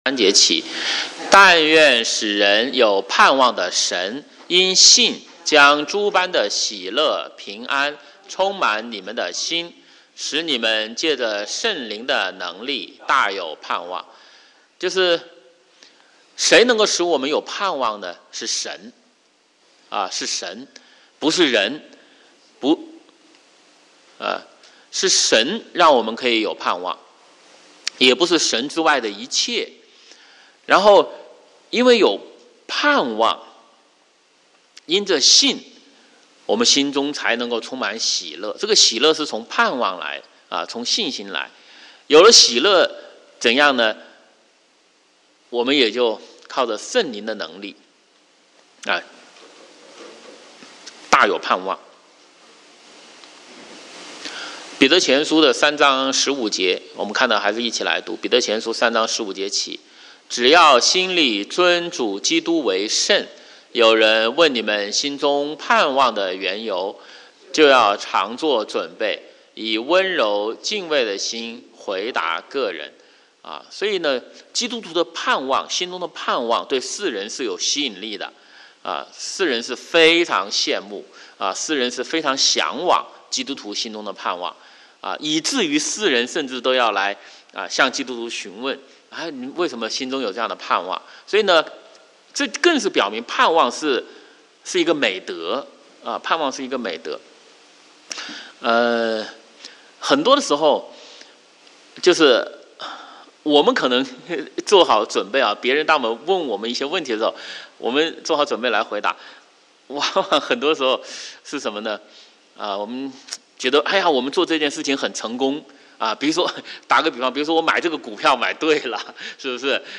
主日学